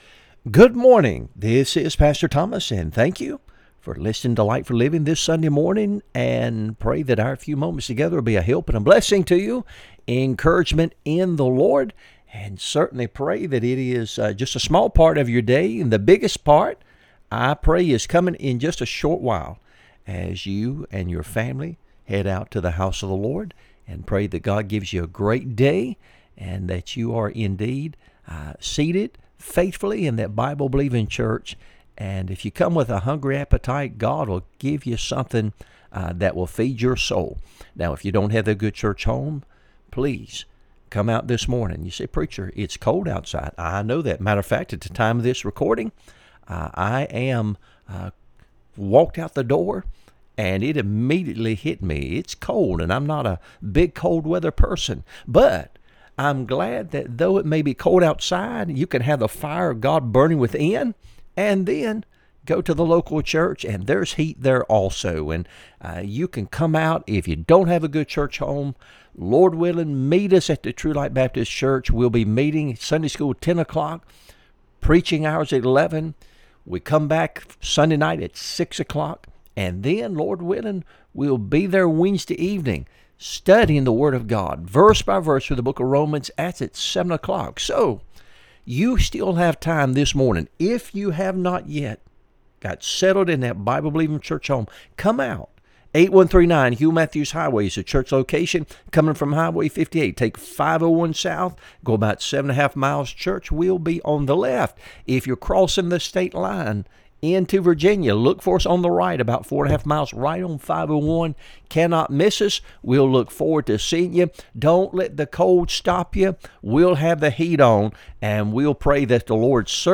Sermons | True Light Baptist Church of Alton, Virginia